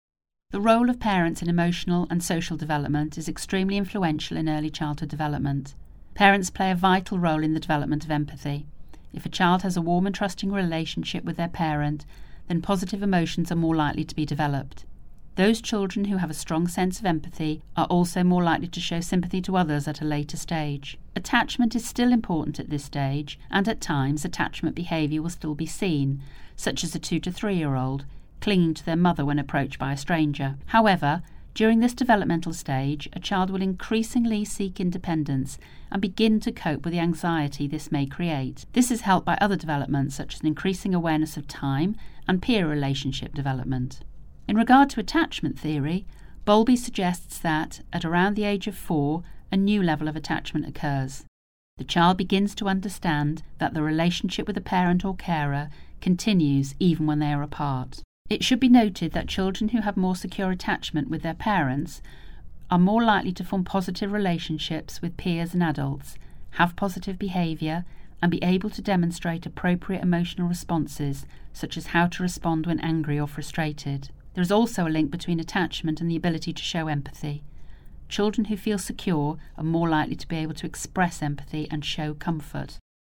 Narration audio (MP3)